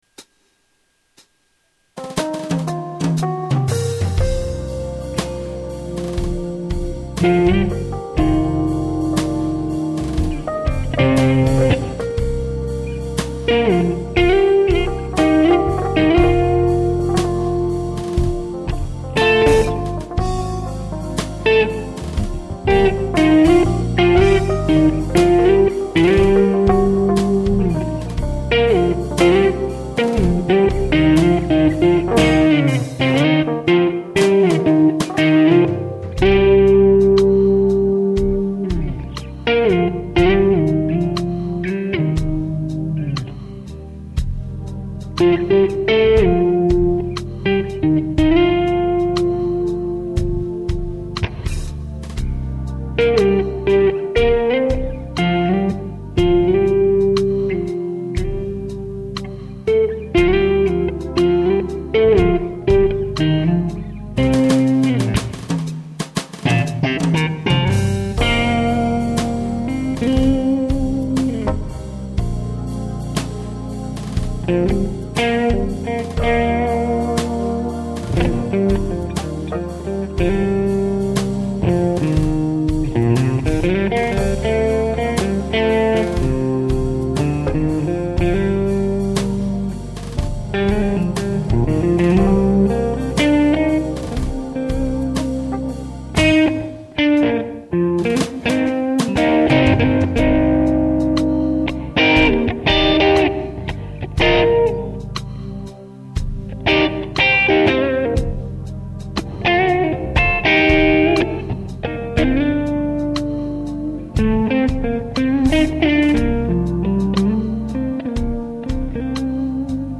Es sind auch wieder ein paar Intonations- und Timing-Meisen dabei ;-)
Warm and big tone.
Nice tone. Just the right amount of spank and then the Plexi simulation smoked.